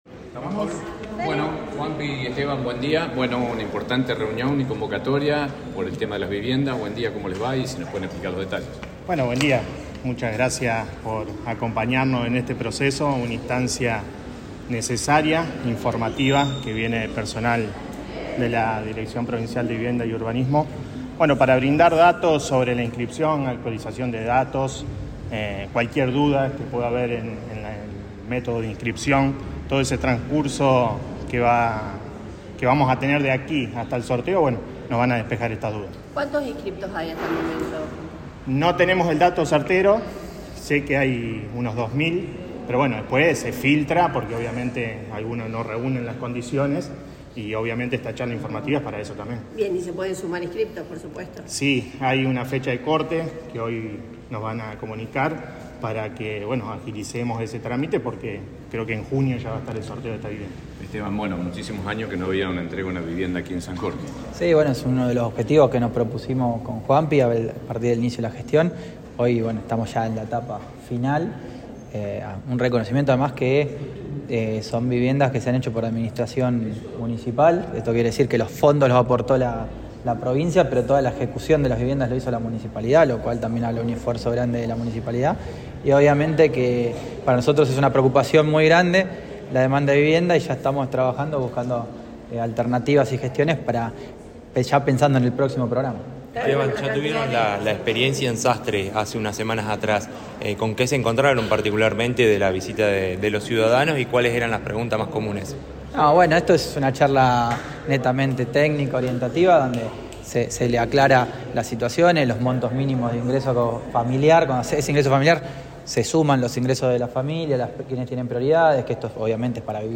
Tanto el senador como el intendente dialogaron con los medios. Se explayaron acerca de los métodos de inscripción para el sorteo.
Escuchá acá la conferencia brindada en la sala “Olga Zubarry” del complejo Cultural Municipal :